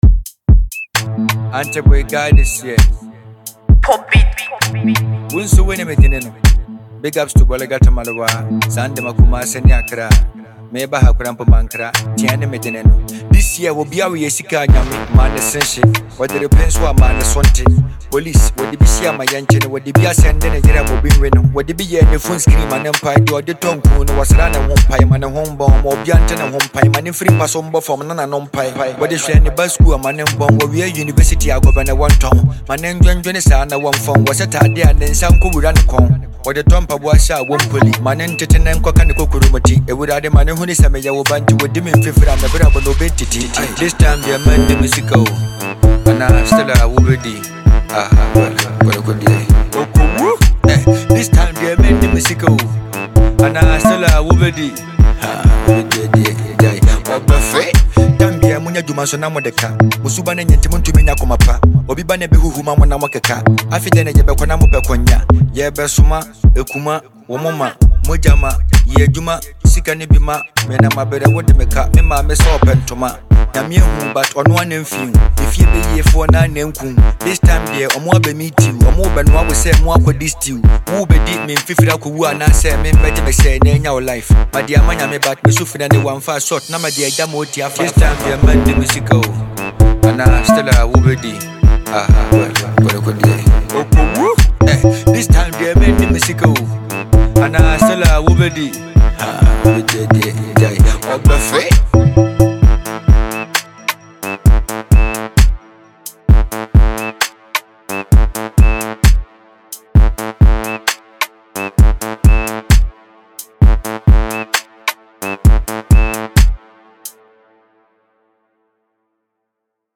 This rap song
smooth instrumentation